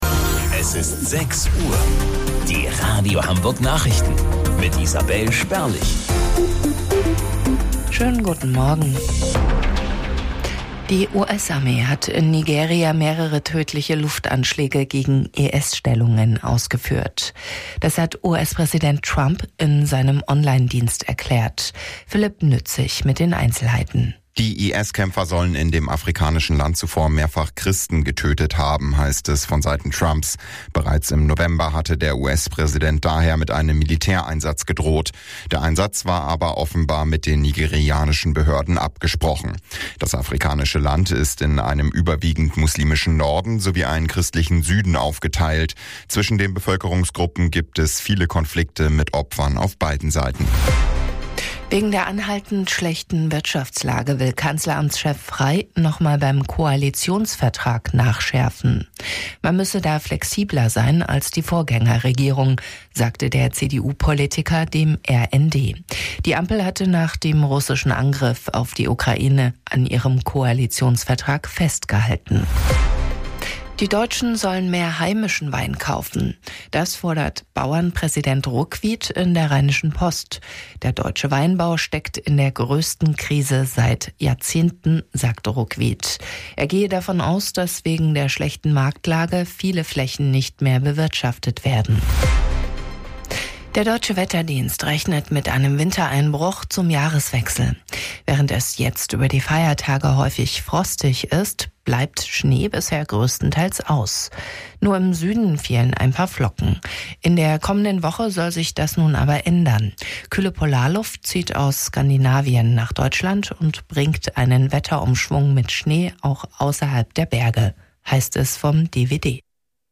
Radio Hamburg Nachrichten vom 26.12.2025 um 06 Uhr